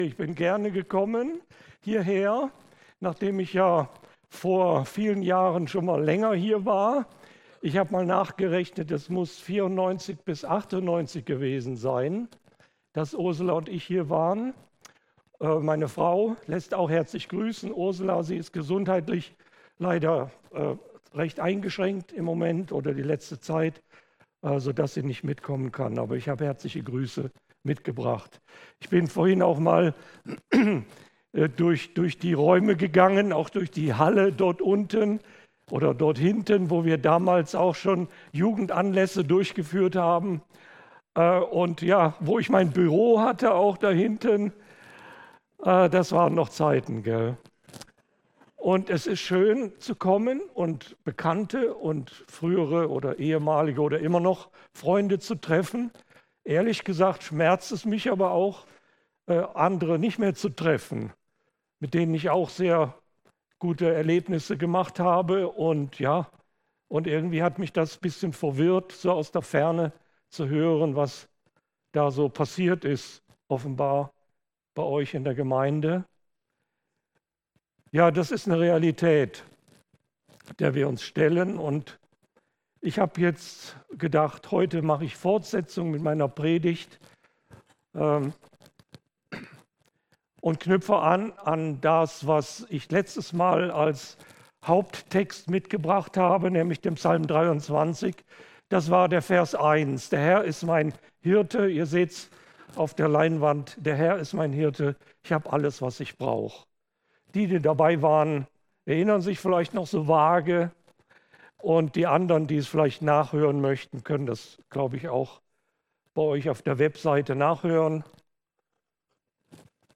Der Herr ist mein Hirte ~ Predigten D13 Podcast
Hier hörst du die Predigten aus unserer Gemeinde.